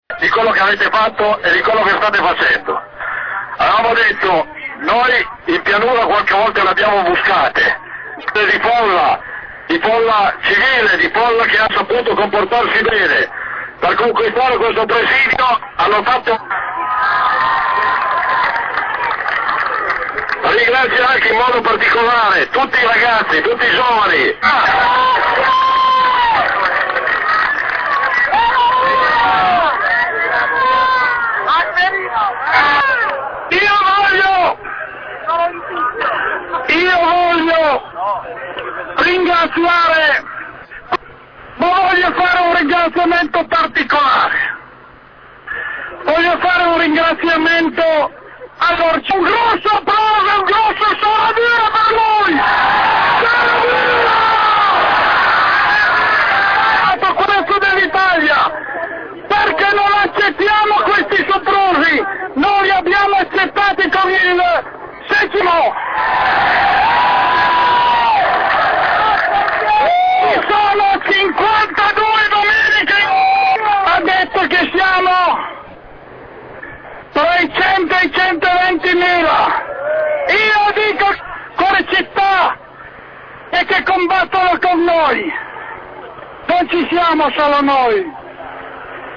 Intervento
continua l'assemblea sul canteire riconquistato